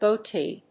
音節goat･ee発音記号・読み方goʊtíː
• / goʊtíː(米国英語)